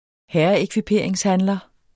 Udtale [ ˈhæɐ̯ʌekviˌpeˀɐ̯eŋsˌhanlʌ ]